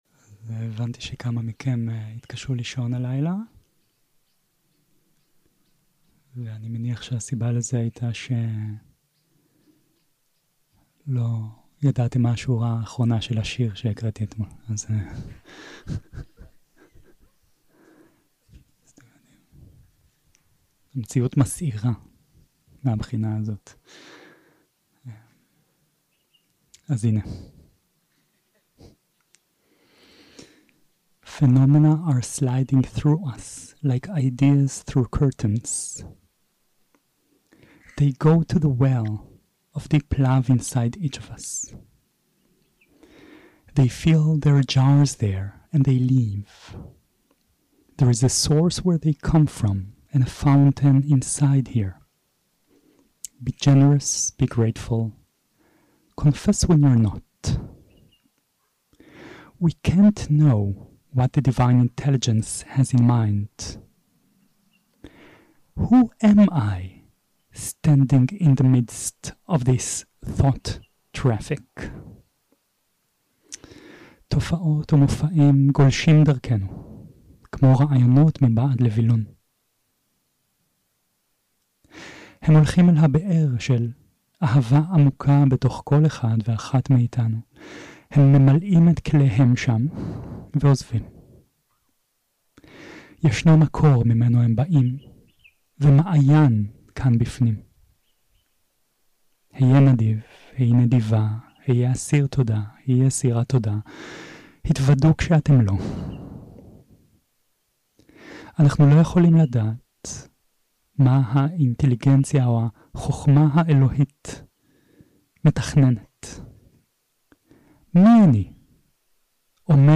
יום 6 - הקלטה 14 - בוקר - הנחיות למדיטציה - מכשולים עדינים, מטא, חלל נרחב 0:00 0:00 סוג ההקלטה: סוג ההקלטה: שיחת הנחיות למדיטציה שפת ההקלטה: שפת ההקלטה: עברית